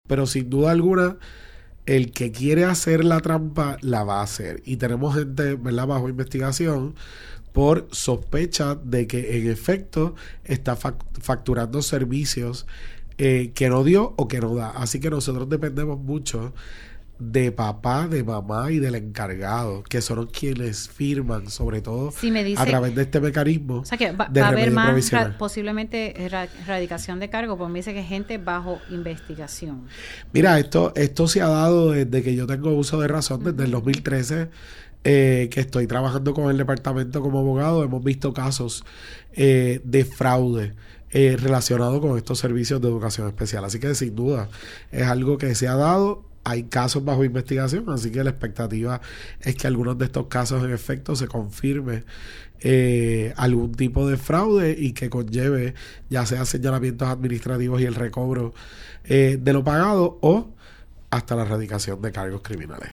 El secretario de Educación, Eliezer Ramos, ofreció en RADIO ISLA 1320 una actualización ante el inicio del año académico 2025- 2026.